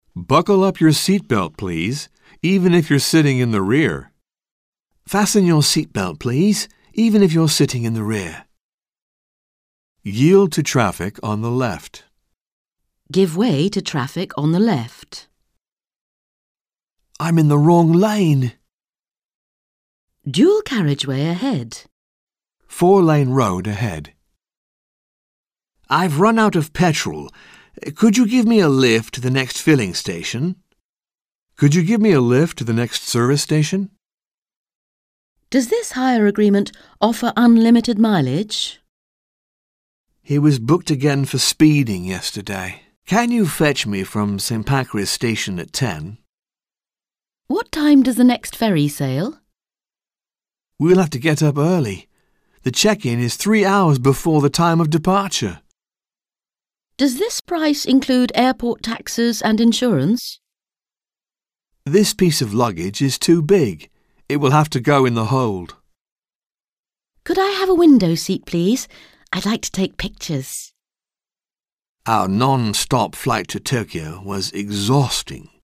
Un peu de conversation - Voyager en train, en avion, en bateau